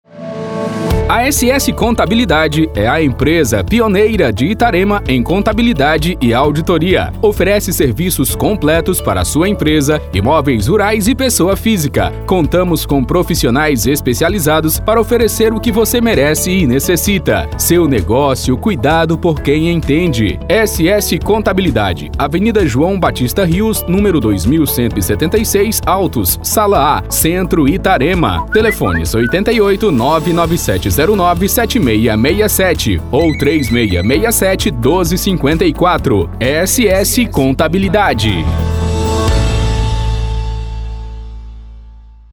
Spot Comercial